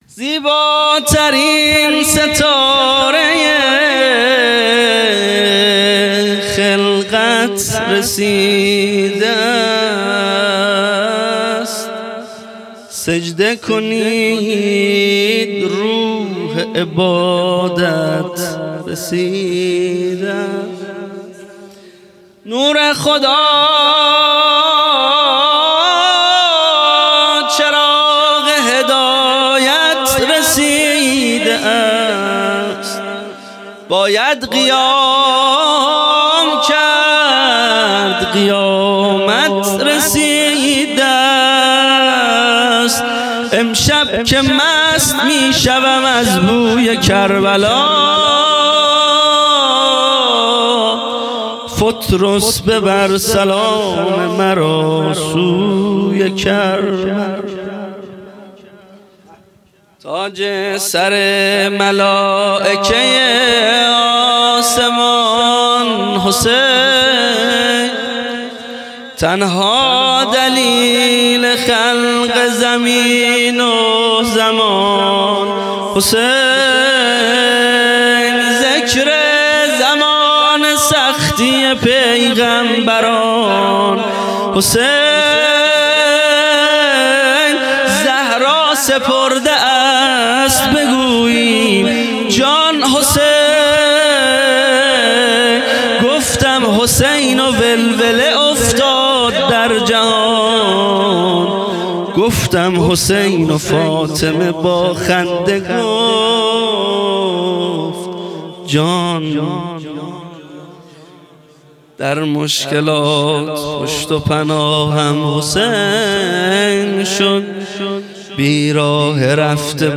خیمه گاه - هیئت بچه های فاطمه (س) - مدح | زیباترین ستارۀ خلقت رسیده است